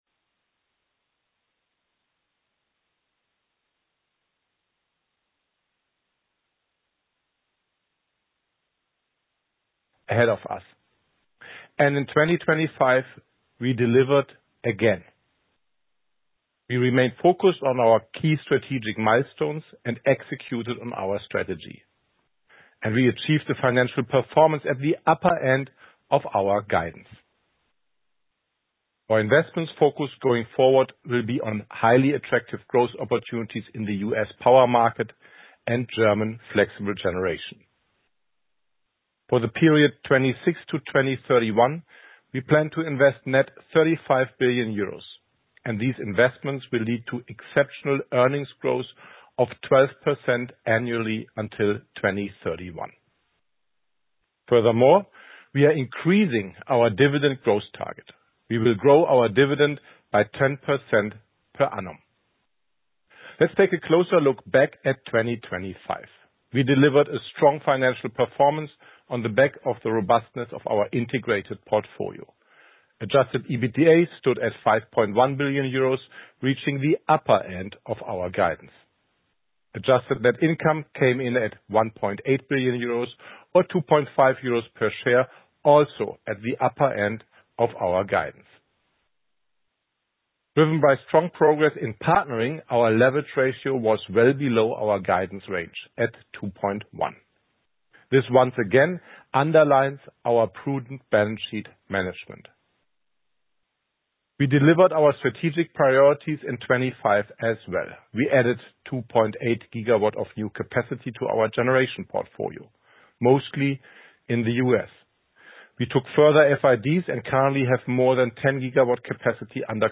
Investor and analyst conference